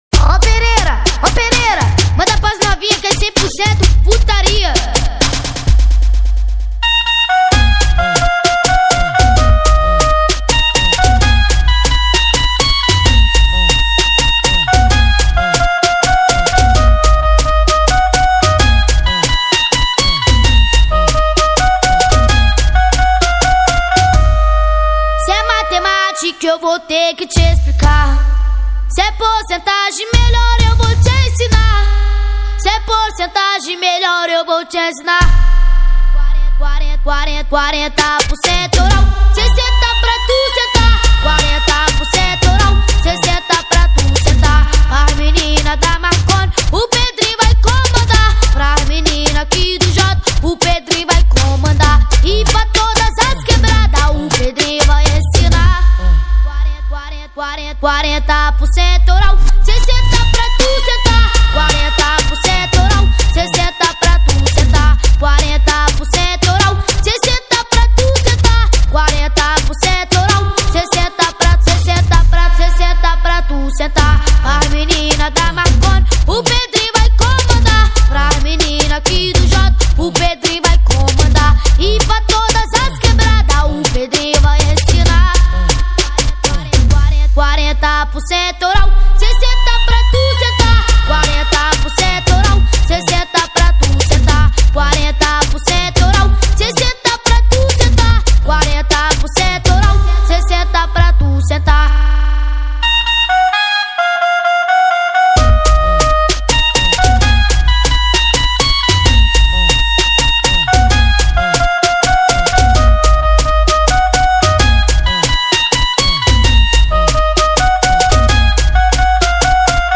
2024-06-23 08:54:50 Gênero: MPB Views